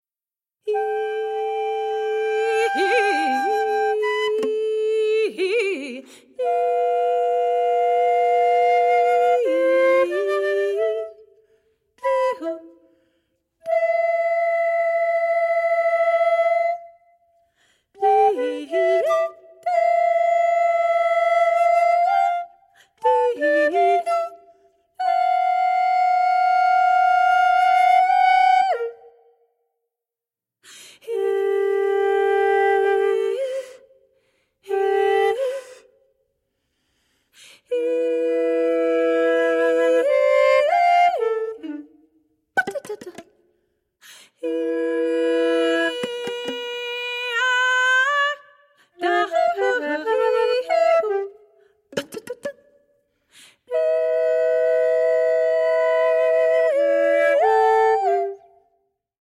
we hear faint, fragile whistle tones in the flute